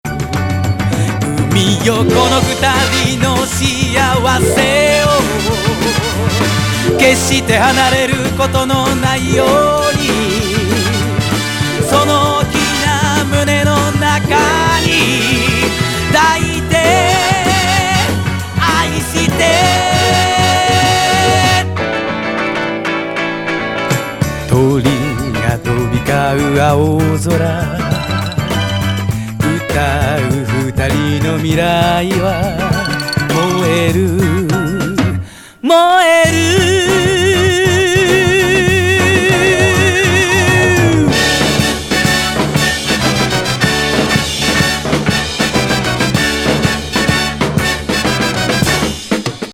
ビッグバンドのファジー・